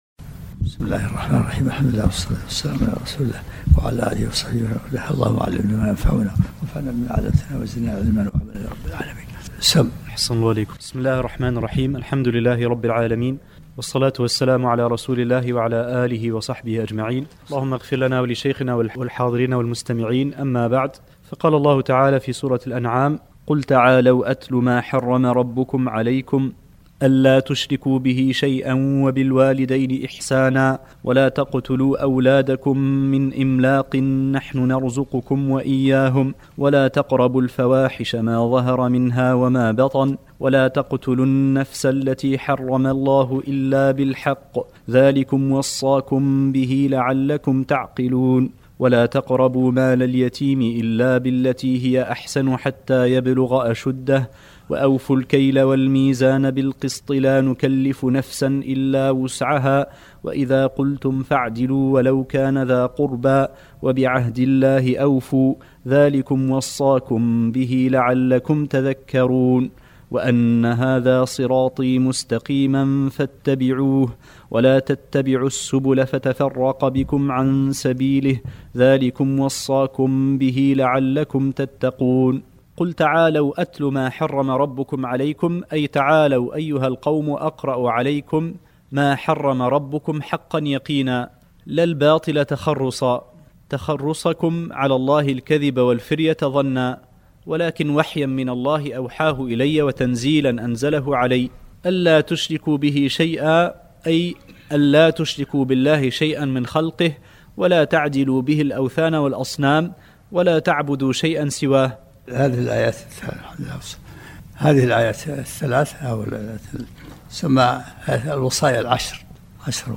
الدرس الثلاثون من سورة الانعام